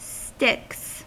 Ääntäminen
UK : IPA : /stɪks/ US : IPA : /stɪks/